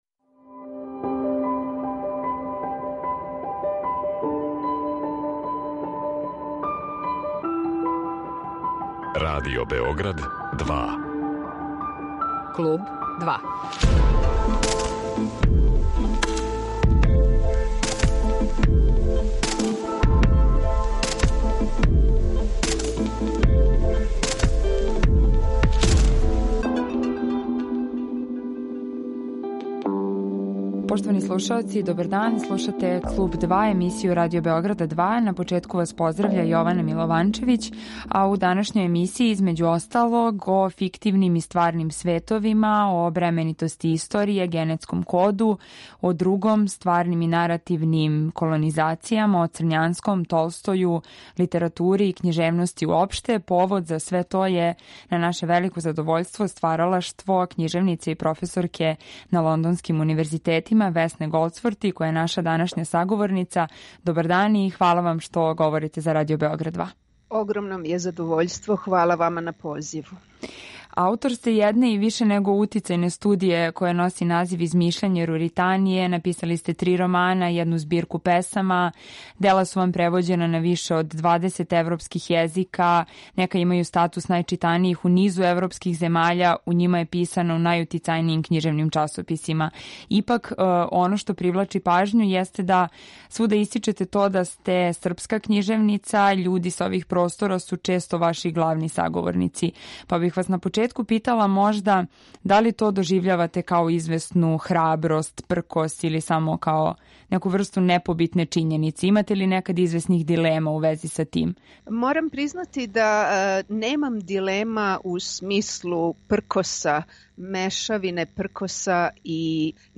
Гошћа данашњег Клуба 2 је књижевница и професорка енглеске књижевности на лондонским универзитетима Весна Голдсворти.